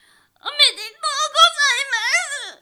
ボイス
ダウンロード 女性_「おめでとうございます」
リアクション